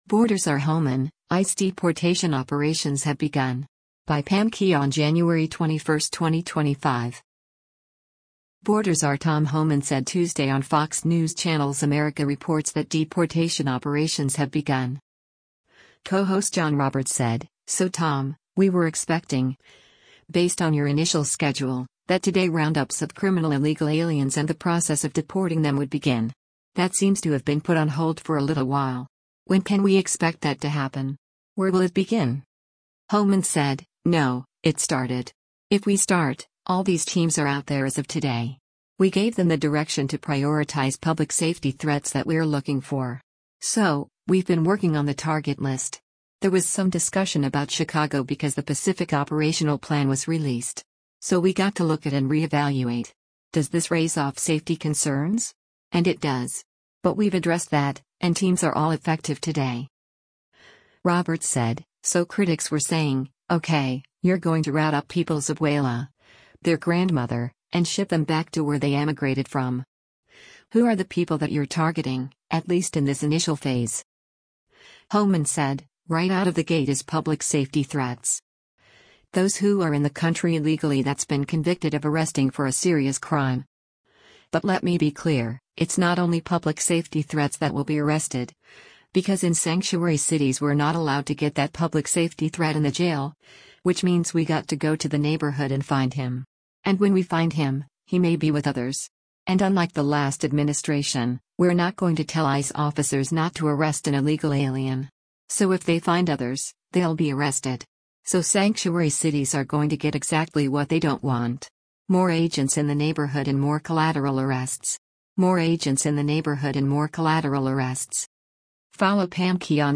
Border czar Tom Homan said Tuesday on Fox News Channel’s “America Reports” that deportation operations have begun.